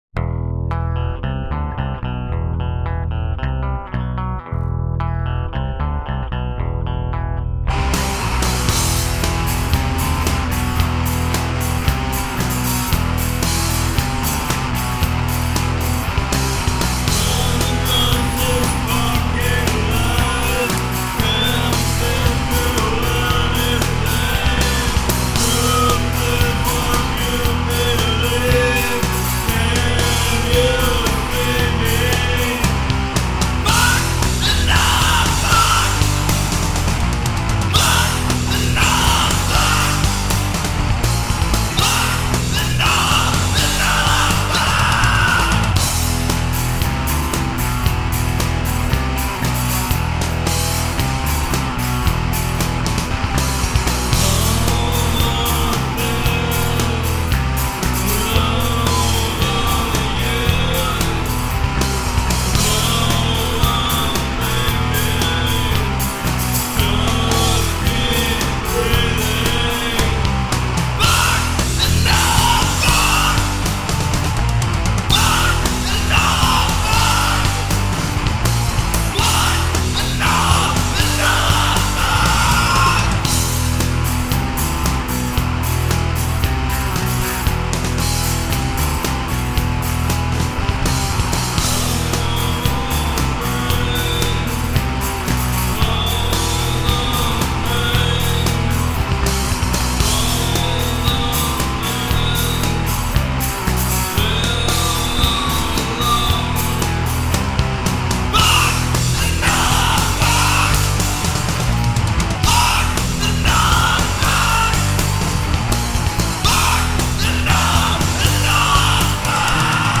putting a somewhat sinister appeal to itself